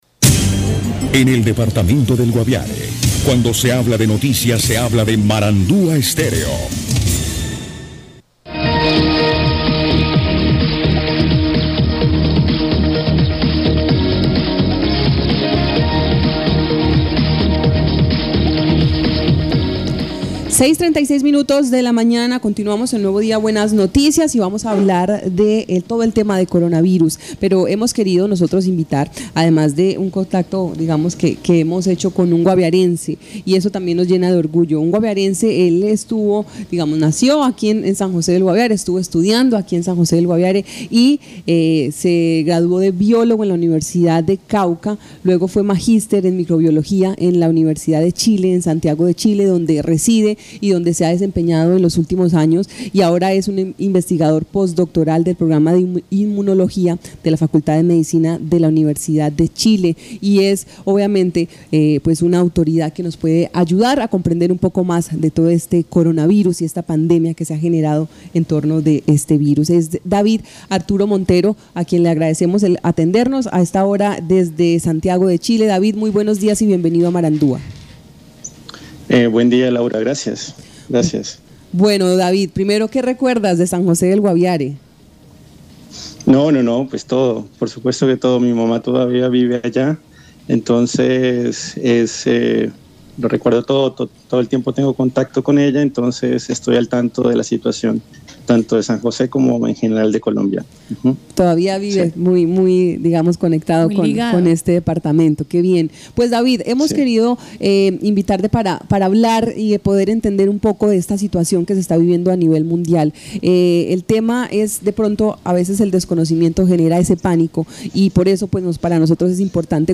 Te puede interesar: Secretaría de Salud del Guaviare desmiente que haya casos de coronavirus Hablamos con el en Nuevo Día, Buenas Noticias y nos explicó en detalle qué es un coronavirus, la diferencia entre un resfriado, una gripa y el COVID19, también nos señaló que los síntomas de alarma por los que las personas deberían asistir al médico son fiebre alta, tos seca y dificultad para respirar, si y solo si presenta estos signos y además tiene el antecedente de haber estado en otro país, de lo contrario, es mejor quedarse en casa y tomar las medidas necesarias. También destacó la importancia de respetar la cuarentena o aislamiento de 14 días que es el periodo de incubación del virus para prevenir que población vulnerable como los adultos mayores de 60 años o con otro tipo de enfermedades previas como diabetes puedan contraer el virus y presentar un impacto mayor en su salud.